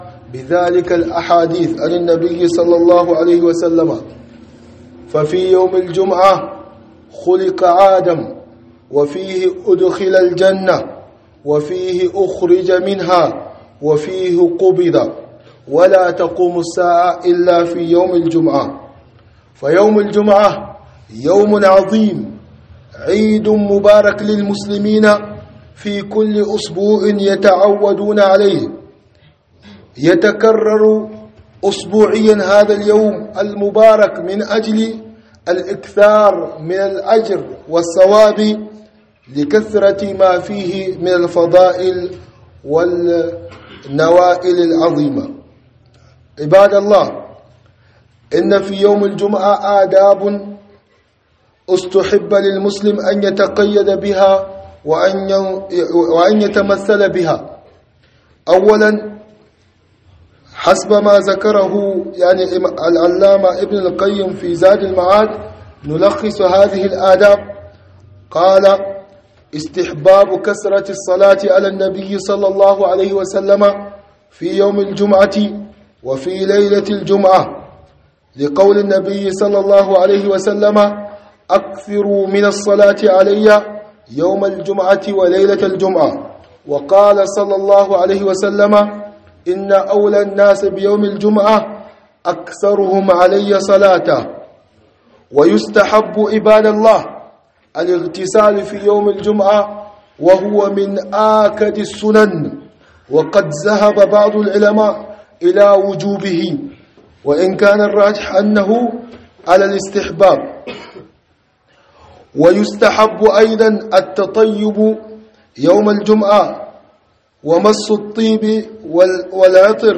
خطبة بعنوان من آداب يوم الجمعة وفضائله